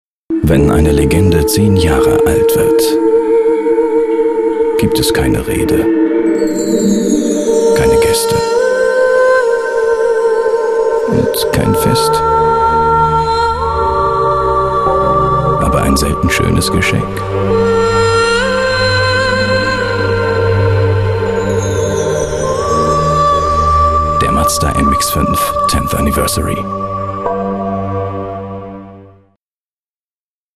deutscher Synchronsprecher, Off-Stimme, Moderator, Werbesprecher, Hörspiel, Trickstimme, Dialekte
Sprechprobe: Sonstiges (Muttersprache):
german voice over artist